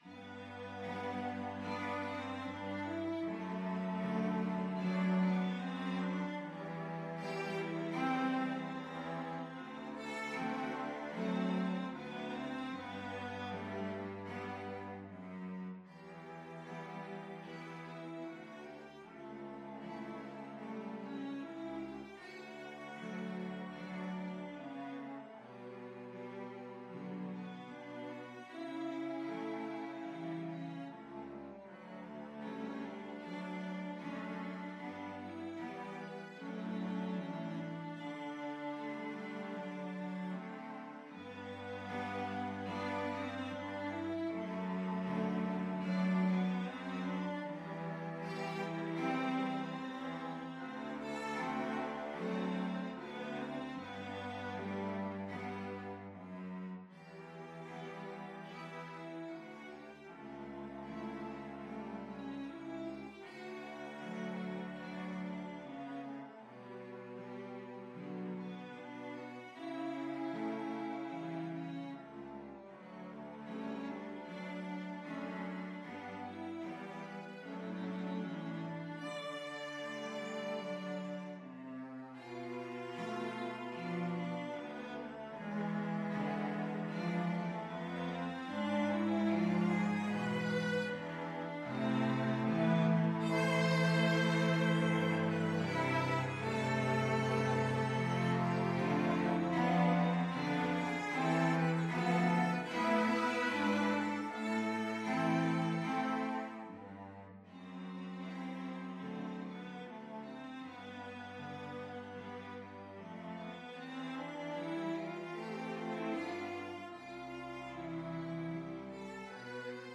Free Sheet music for Cello Quartet
Cello 1Cello 2Cello 3Cello 4
4/4 (View more 4/4 Music)
G major (Sounding Pitch) (View more G major Music for Cello Quartet )
Andante = c.76
Classical (View more Classical Cello Quartet Music)